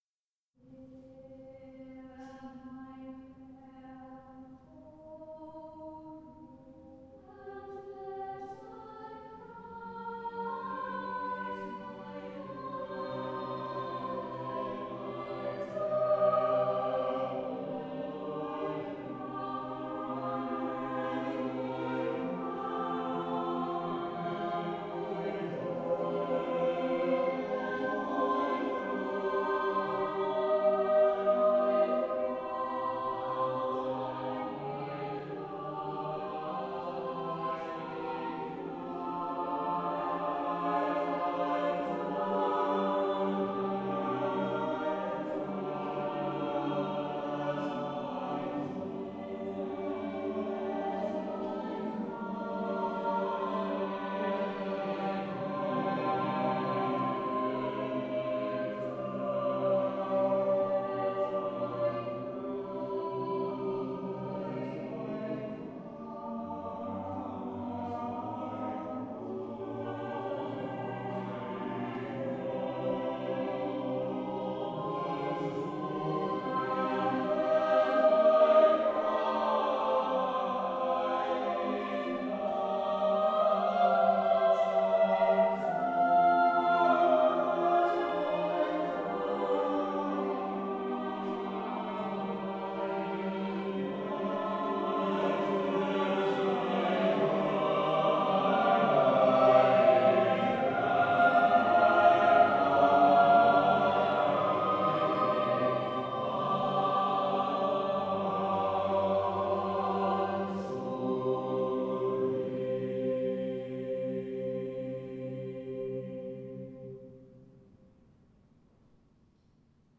English choral music as it might have been sung
in the Priory Church through the centuries